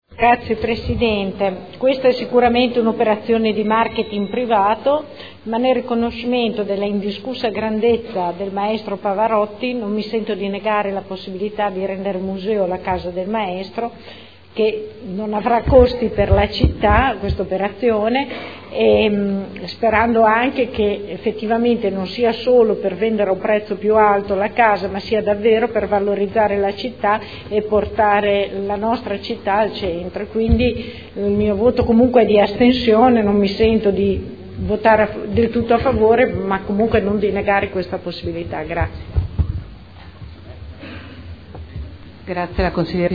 Seduta del 31 marzo.
Dichiarazioni di voto